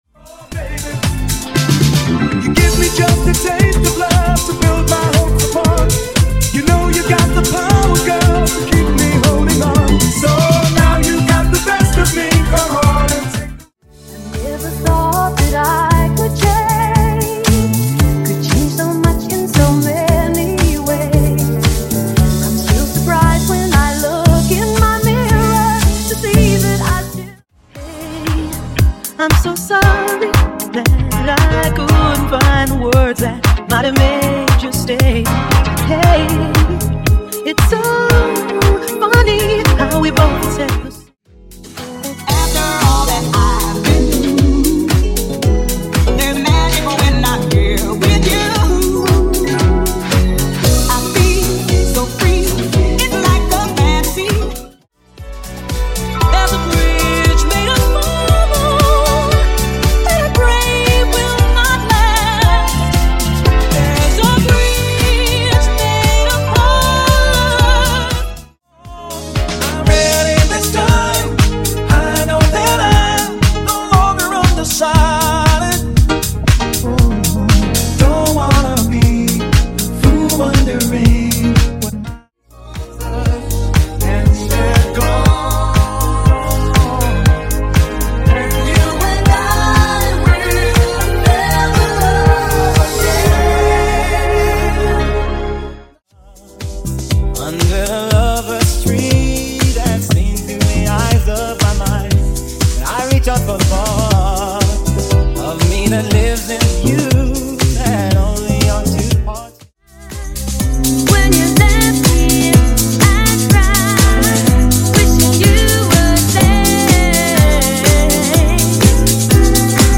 Genres: MASHUPS , R & B , TOP40
Dirty BPM: 111 Time